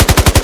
gun.ogg